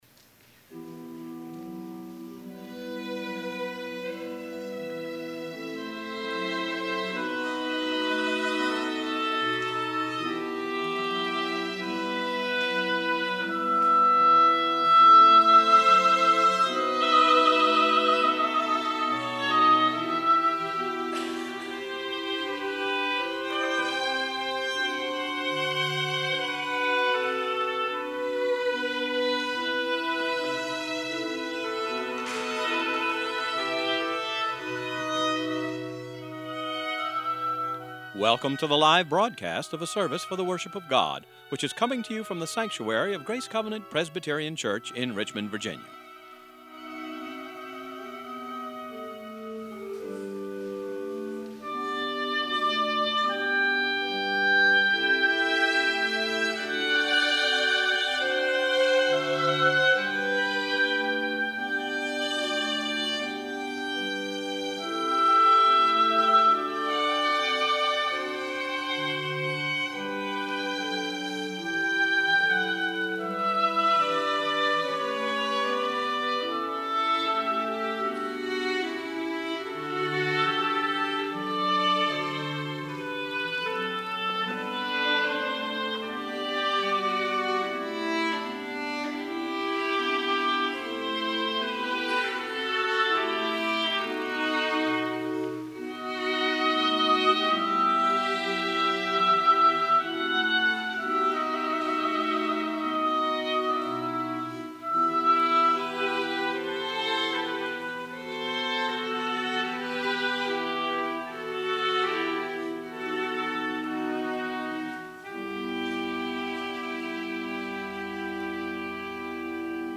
VOLUNTARY Gabriel’s Oboe Ennio Morricone
oboe
organ